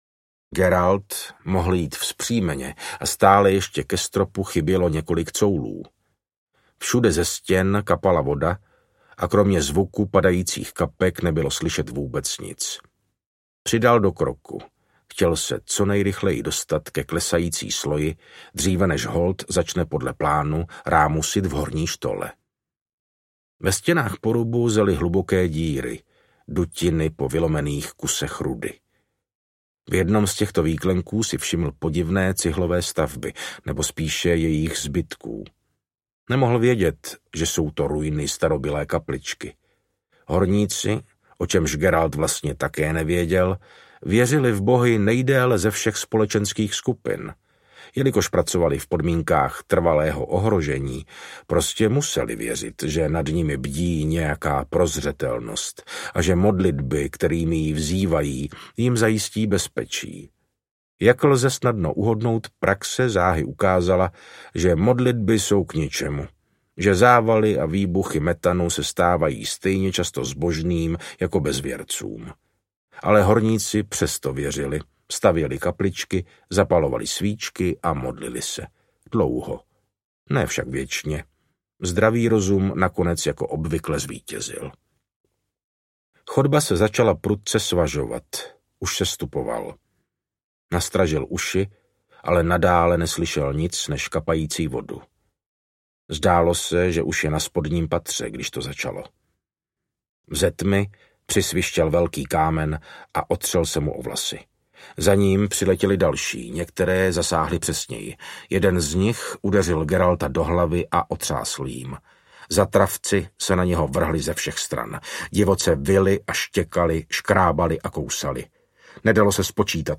Rozcestí krkavců audiokniha
Ukázka z knihy